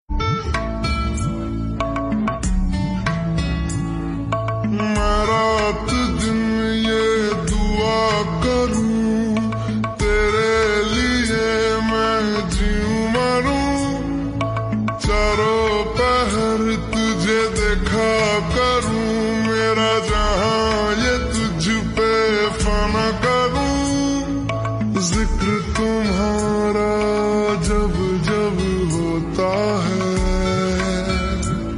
Hindi Songs
(Slowed + Reverb)